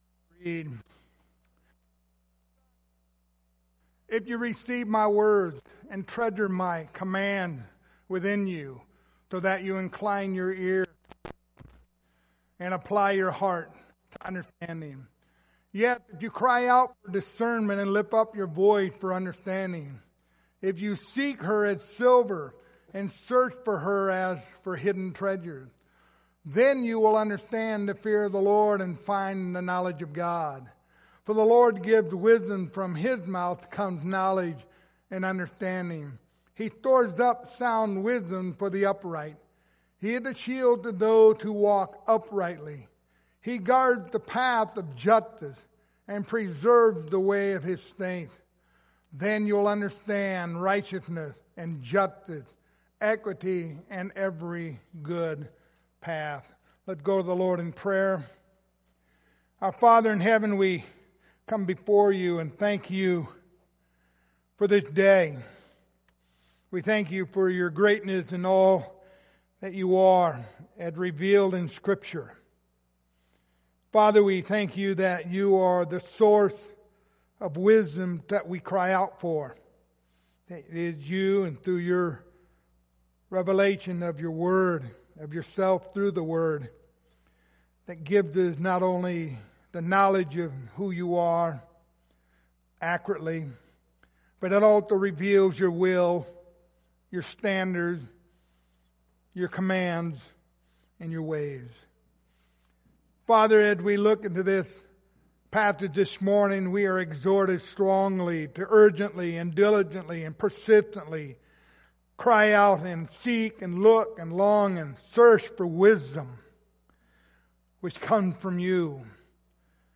The Book of Proverbs Passage: Proverbs 2:1-5 Service Type: Sunday Morning Topics